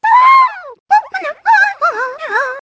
One of Wiggler's voice clips in Mario Kart 7